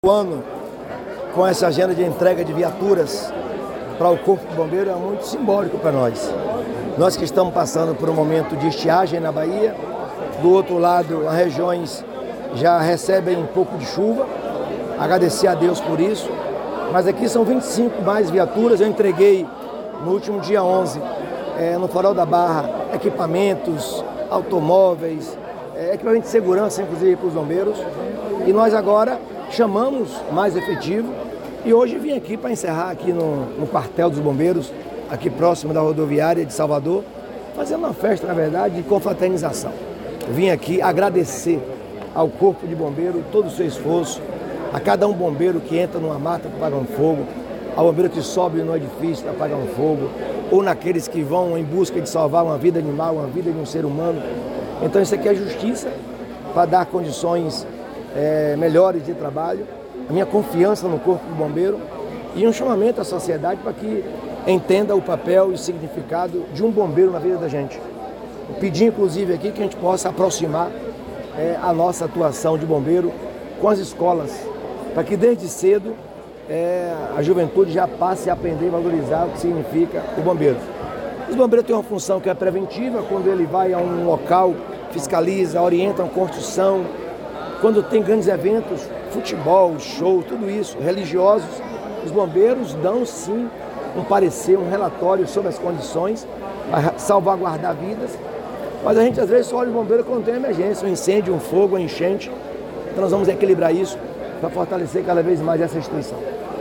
🎙Governador Jerônimo Rodrigues